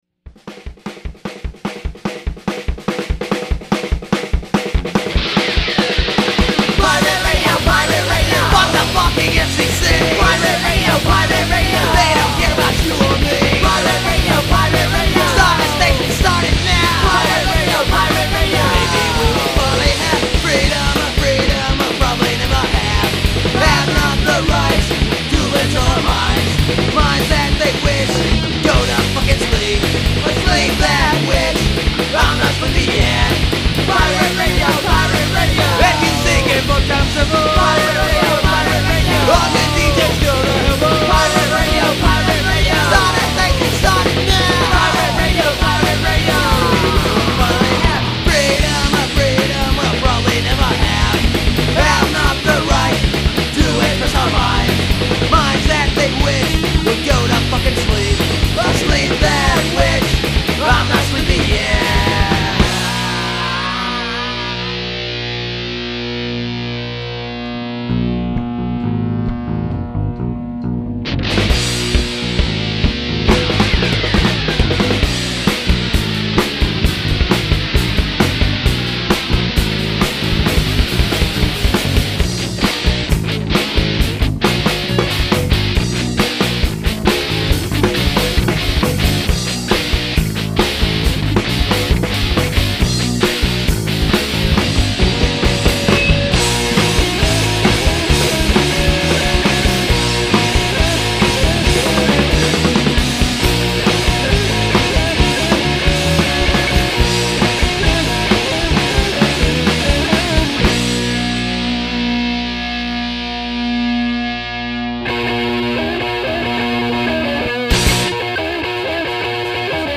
Raw punk from Philadelphia with attitude.